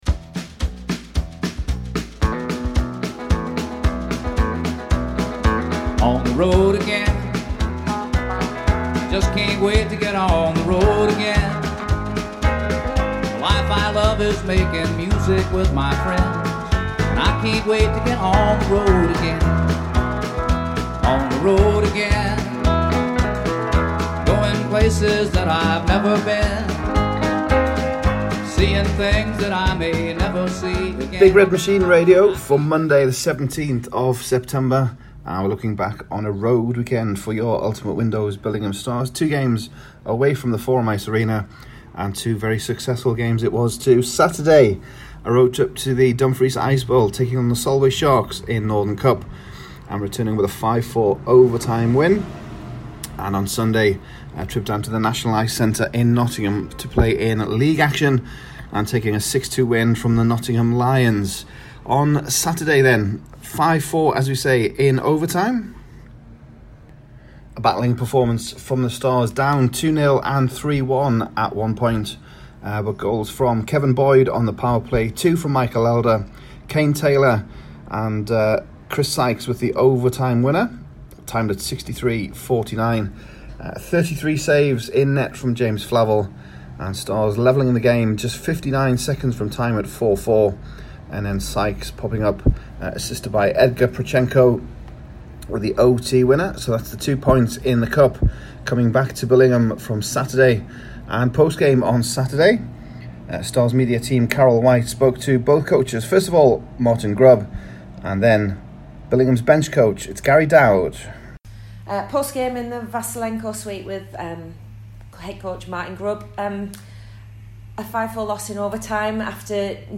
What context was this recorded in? BigRedMachine Radio was in Dumfries and Nottingham this weekend with the Ultimate Windows Billingham Stars, getting post-game reaction from the 5-4 OT cup win on Saturday and the 6-2 league win on Sunday.